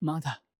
戦闘 ダメージ ボイス 声素材 – Damage Voice